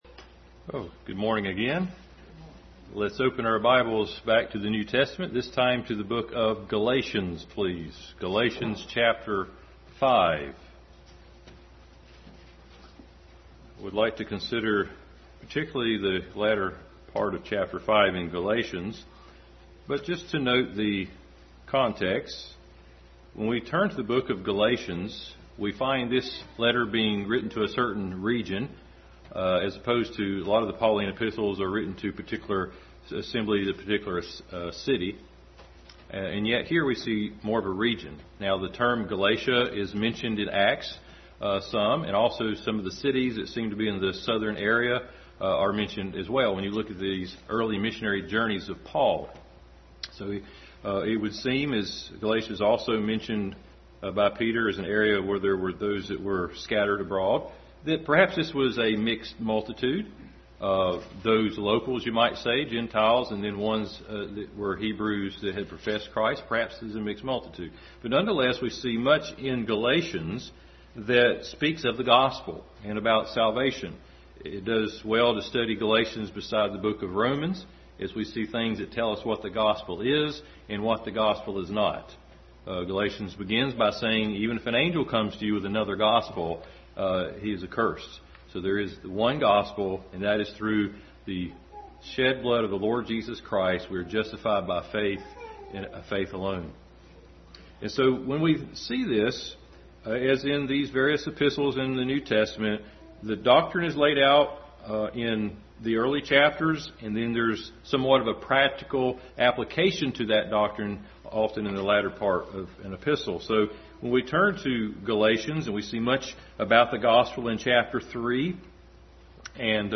In The Spirit Passage: Galatians 5:13, James 1:25, 2:12, 2:8, Romans 8:5-11, 2 Peter 1:5-8, Galatians 4:27-31 Service Type: Family Bible Hour Family Bible Hour message.